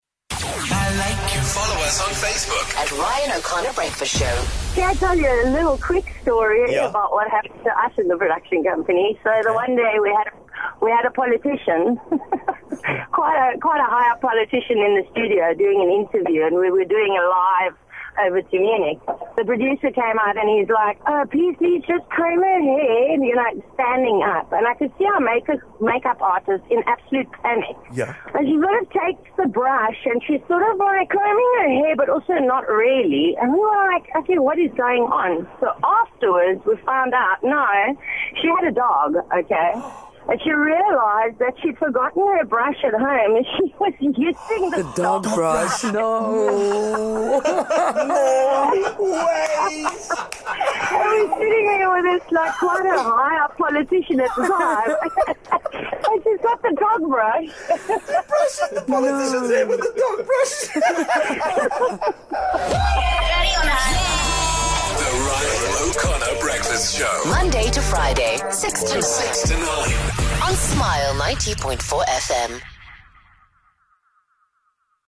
Have you ever had a moment when you did something at work that left you embarrassed and scared for your life? One caller told us about her experience working in a production company overseas that involved a well known German politician, a make-up artist, and a dog's hairbrush.